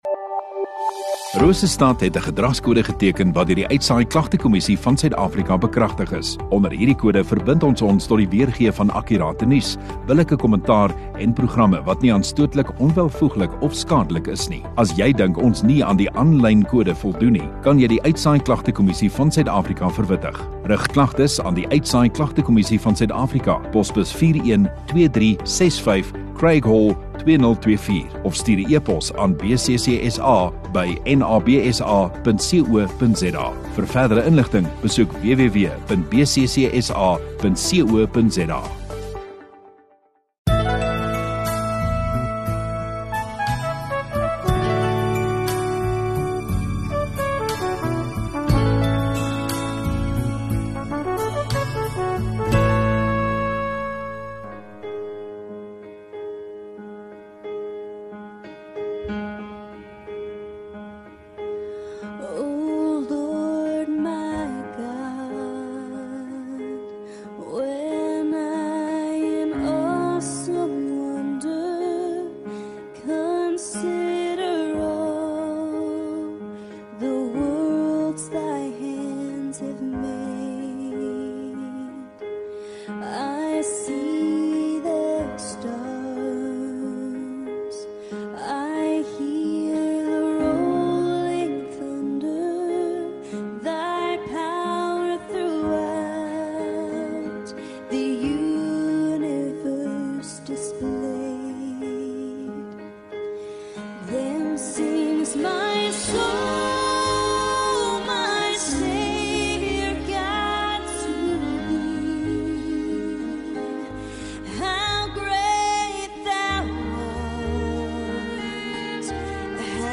29 Jun Sondagoggend Erediens